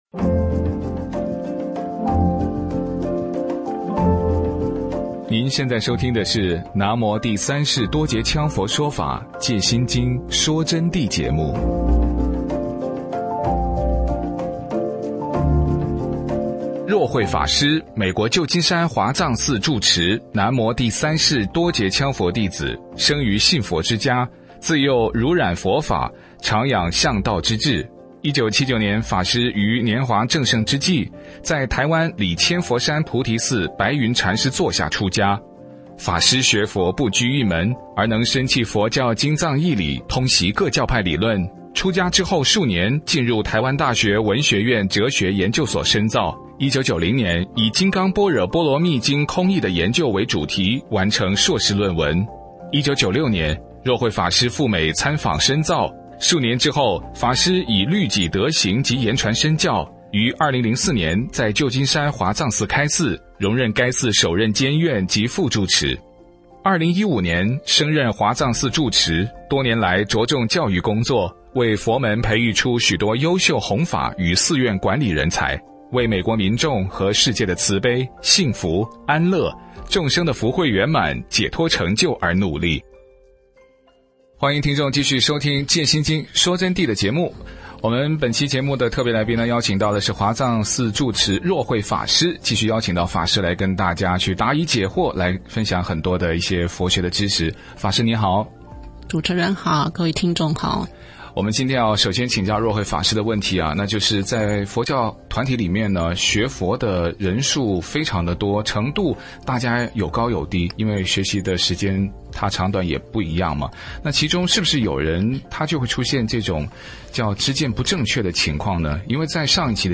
佛弟子访谈（十七）佛教团体中犯所知障的几种现象与如何破除所知障